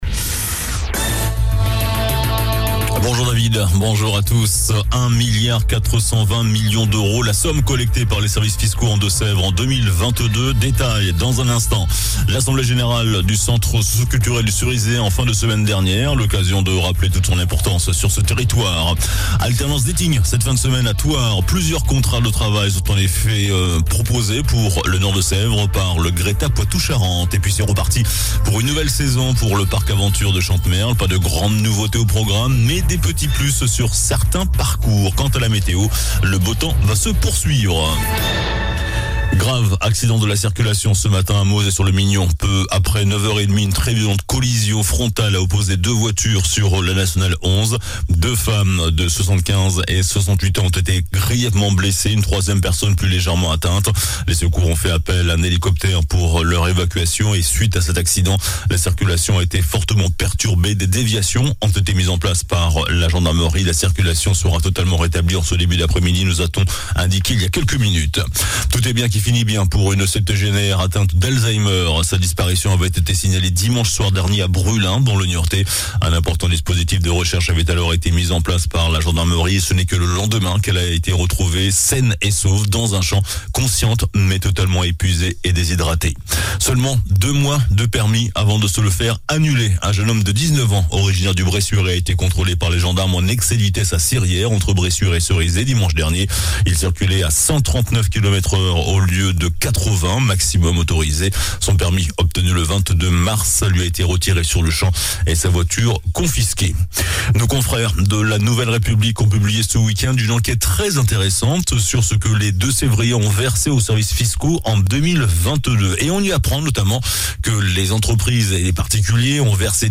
JOURNAL DU MARDI 30 MAI ( MIDI )